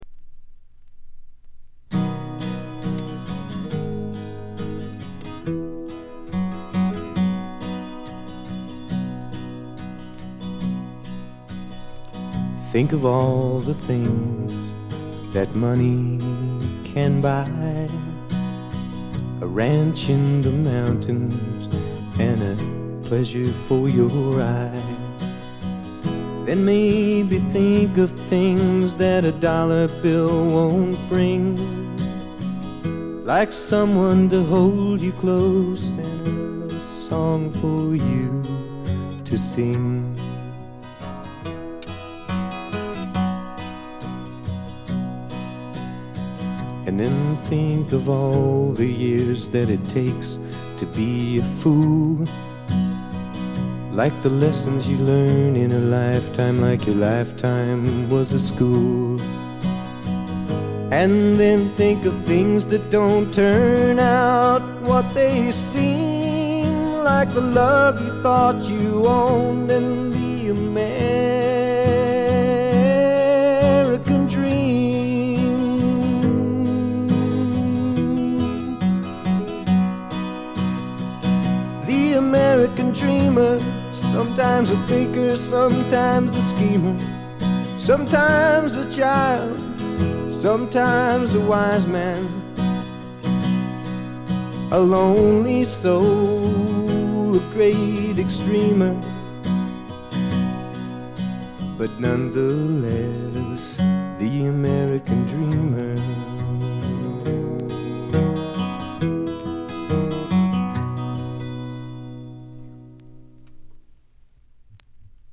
ほとんどノイズ感無し。
全編がフォーキーなアウトローの歌の数々。
試聴曲は現品からの取り込み音源です。